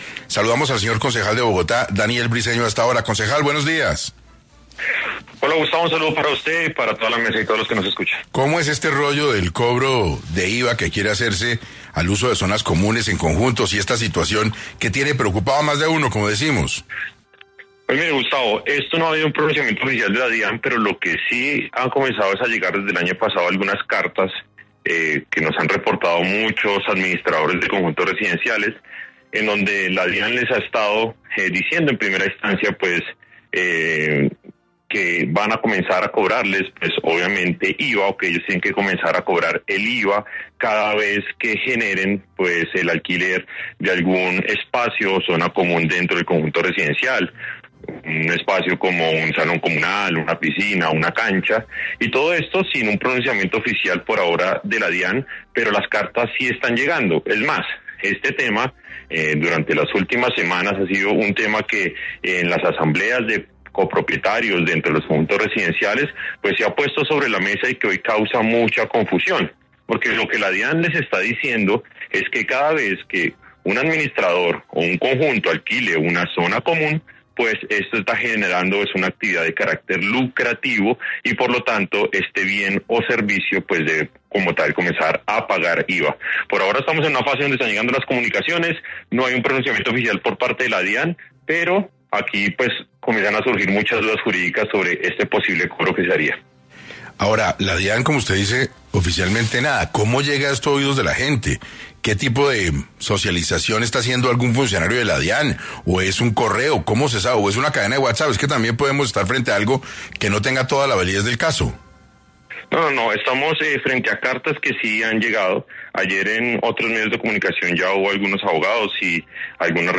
Hoy en entrevista para 6AM,Daniel Briceño, concejal, explicó cuál sería el cobro del IVA que la DIAN quiere implementar en los conjuntos residenciales.